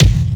Kick_07.wav